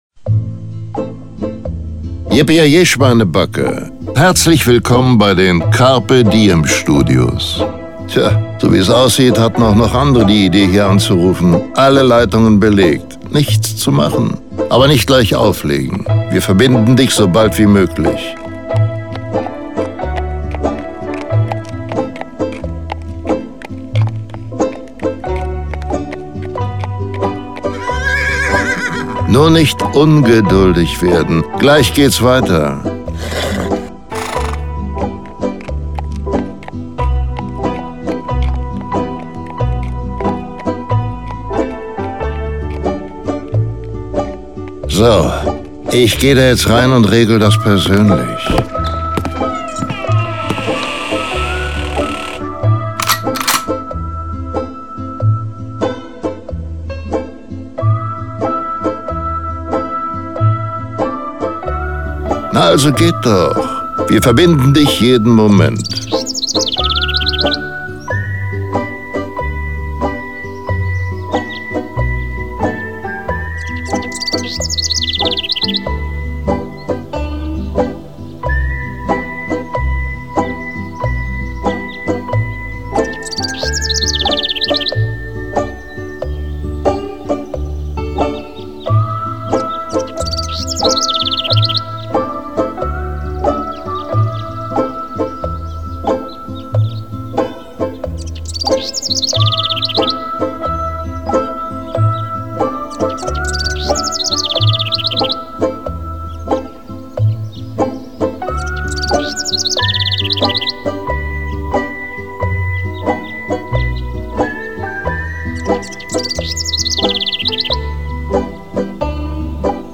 versüßen Sie somit die Wartezeit durch erfrischende Sprüche und Effekte.
Telefon-Warteschleife – Carpe Diem Studios II: